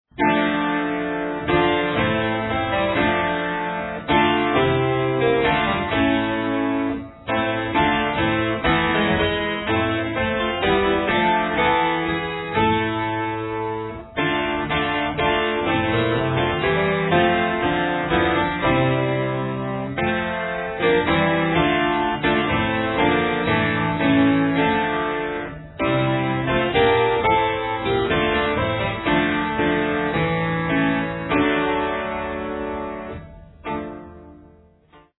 Early stereo master tapes, recorded at 30 ips
partsong for 4 voices